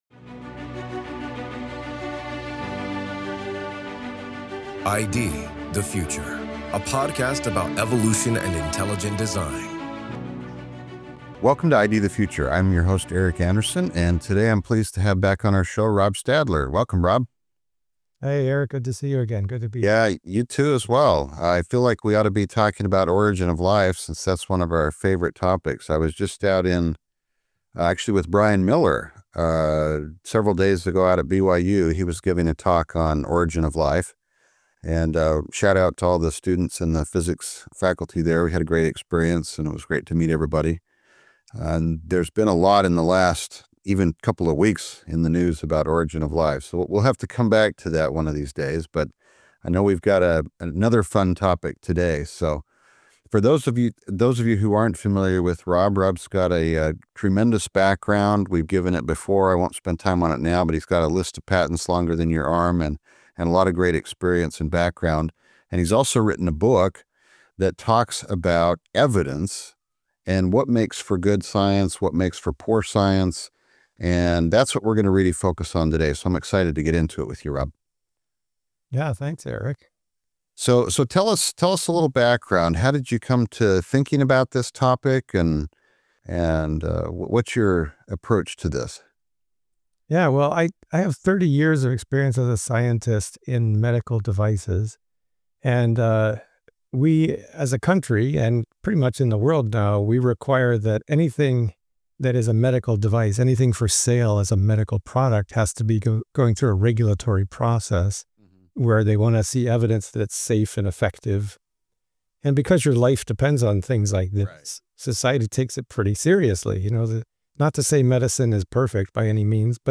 This is Part 1 of a two-part discussion.